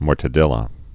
(môrtə-dĕlə)